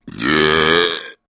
Звуки отрыжки
Громкий звук мужской отрыжки с неприятным запахом